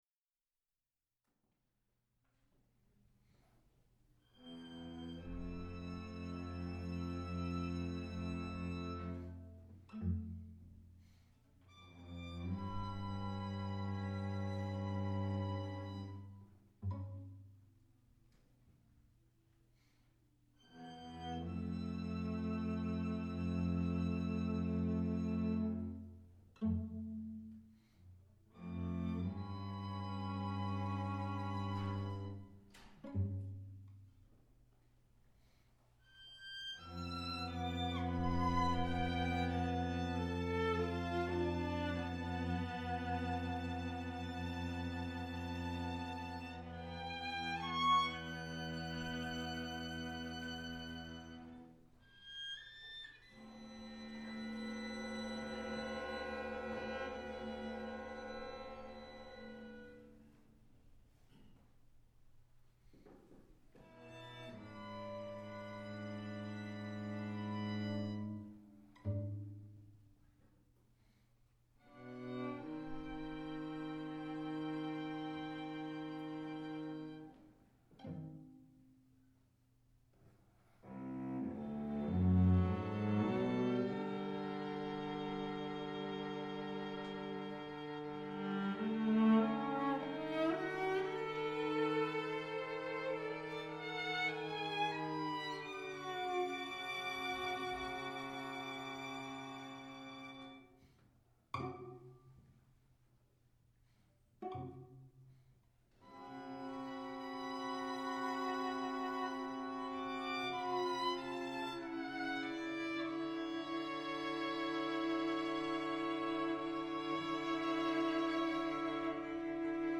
Portland Community Music Center
Distance (2010) Nova String Trio
because of their ability to create smooth glissandi (slides).
exactly half-way through the piece and then receding in the same way.